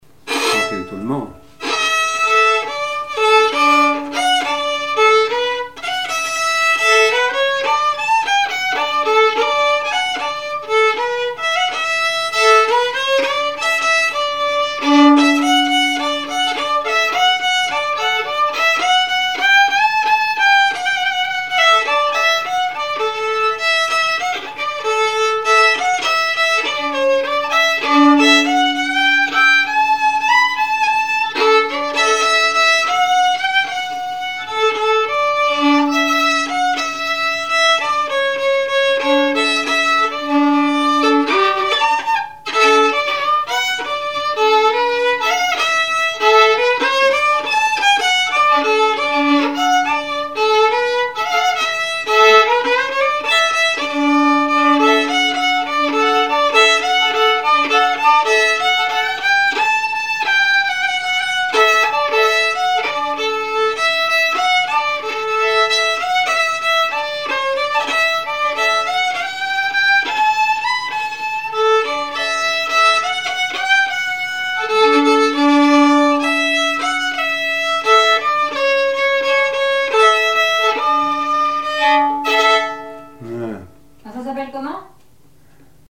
danse : valse
Genre strophique
Témoignages et chansons
Pièce musicale inédite